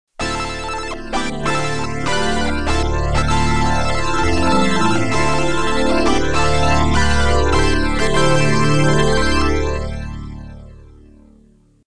ARP String Sounds
The chorus has three separate delays modulated at different amplitudes and frequencies.
This is what my Reaktor ARP impersonations sound like: